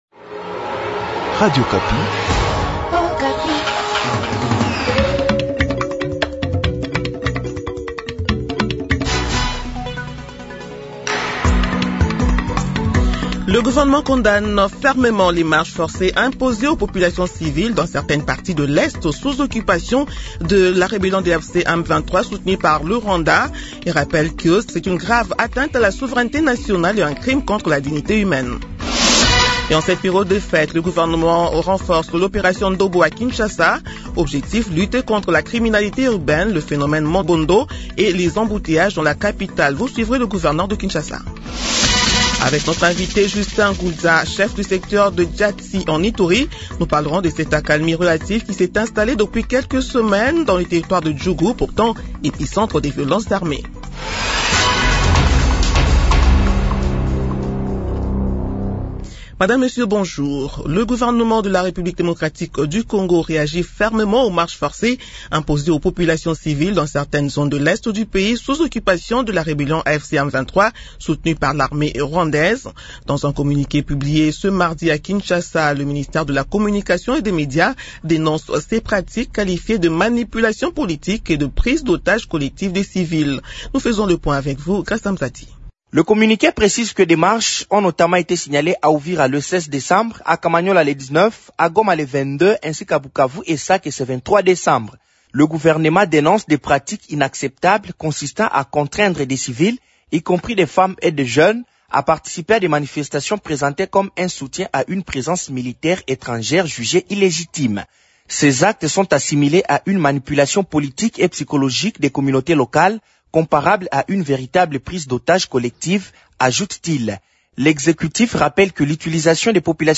Journal Midi Mercredi 24 décembre 2025